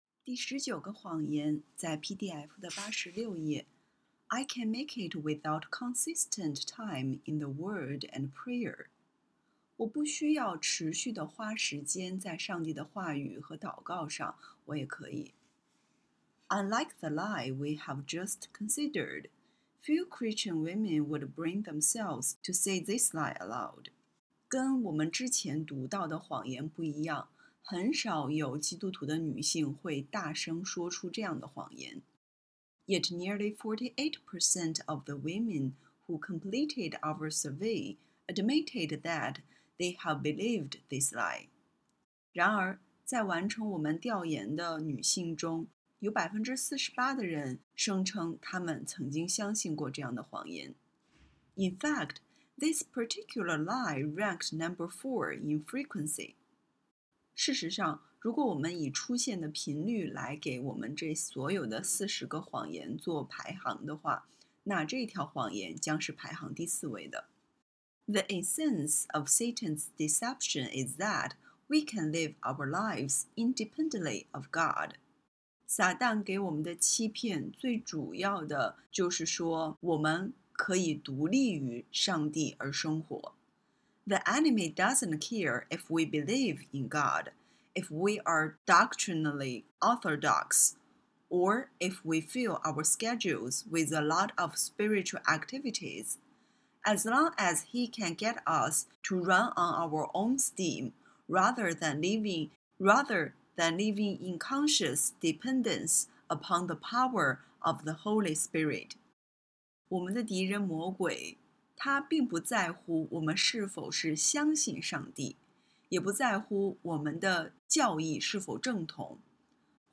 听我为你讲一本英文书《女人们所相信的谎言》请点击音频，每句英文后有翻译。